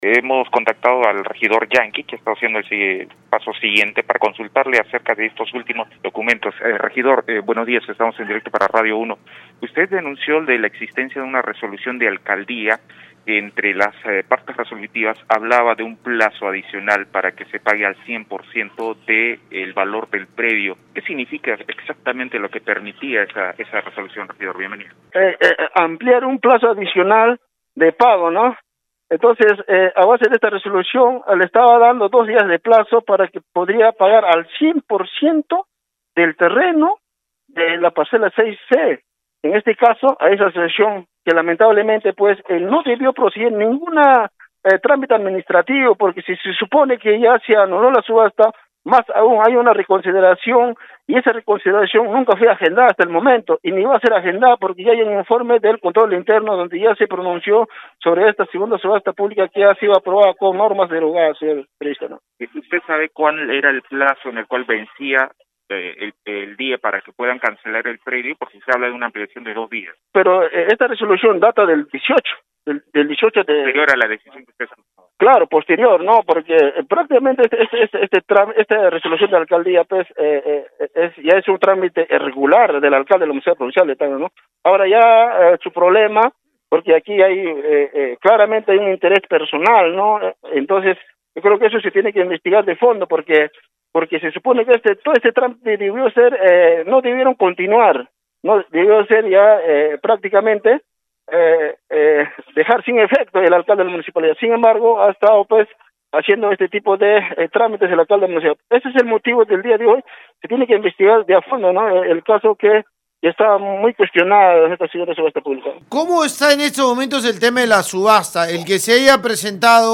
REGIDOR.mp3